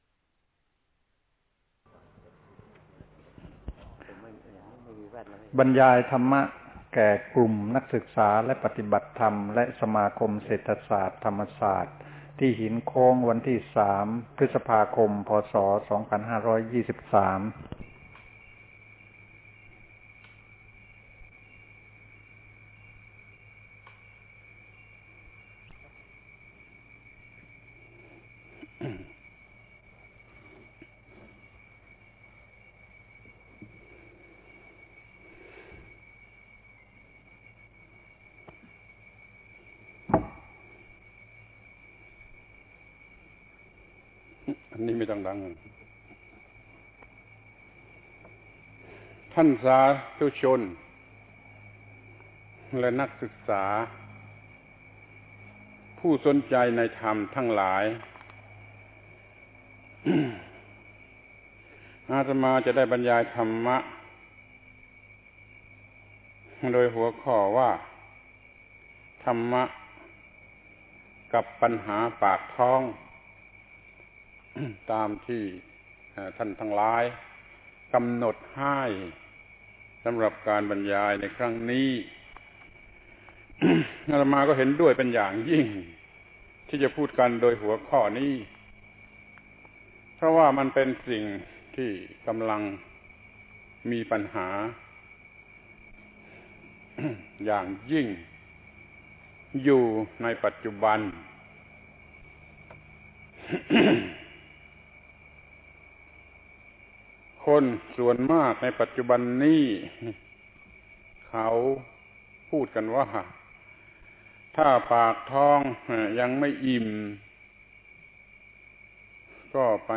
พระธรรมโกศาจารย์ (พุทธทาสภิกขุ) - อบรมนักศึกษามหาวิทยาลัยธรรมศาสตร์ ปี 2523 ครั้ง 1 ธรรมะกับปัญหาปากท้อง